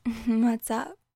E Girl Whats Up Sound Effect Free Download